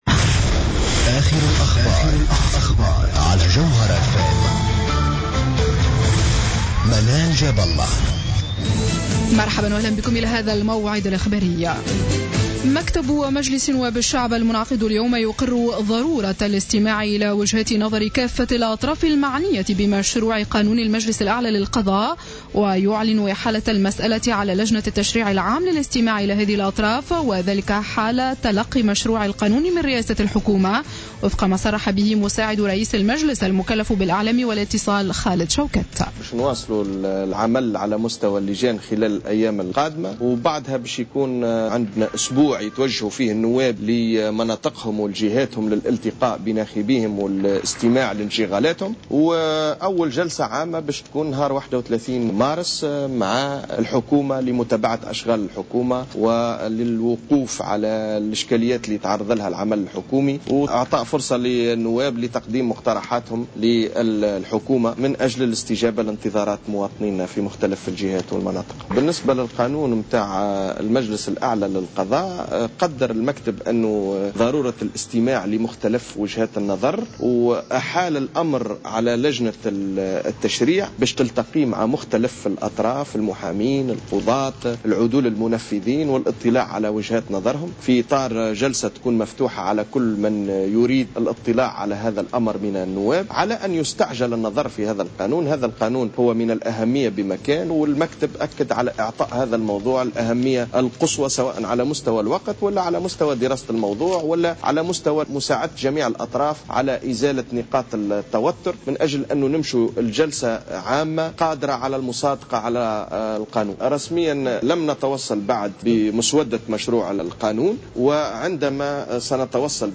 نشرة أخبار السابعة مساء ليوم الخميس 12 مارس 2015